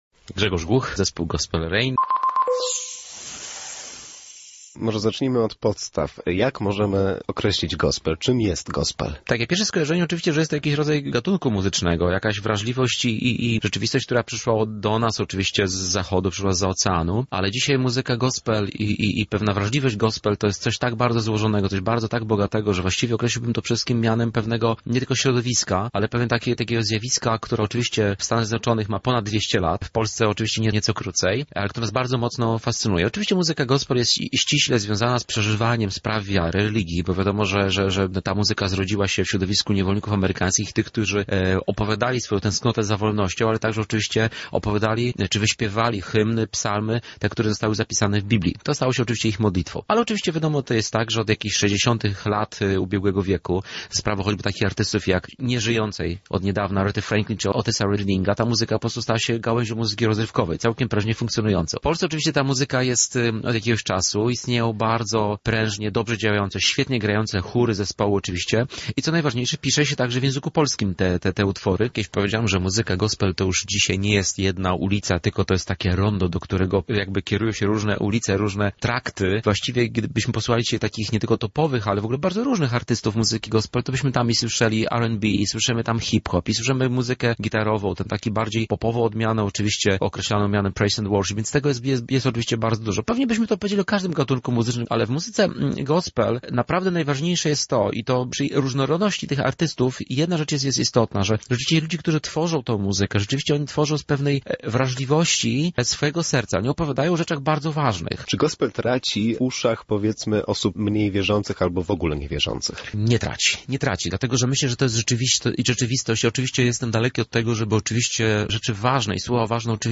W Świdniku odbędą się siódme warsztaty z tego charakterystycznego nurtu muzycznego. Na temat tego czym jest gospel, dlaczego polskie kościoły podchodzą do niego sceptycznie, a także o Światowych Dniach Młodzieży – rozmawialiśmy z weteranem tej muzyki: